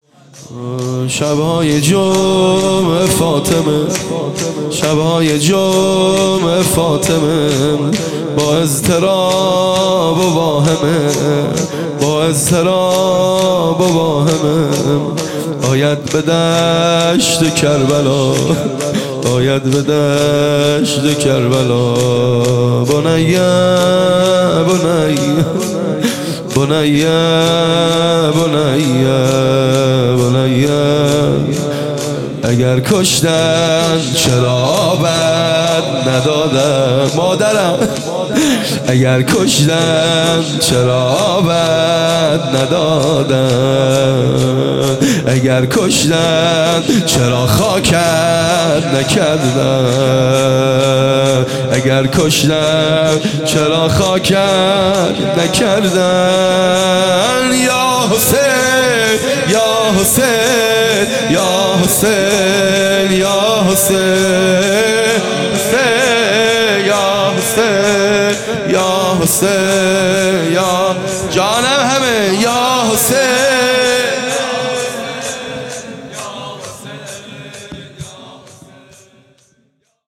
خیمه گاه - هیئت بچه های فاطمه (س) - شور | شبای جمعه فاطمه
جلسۀ هفتگی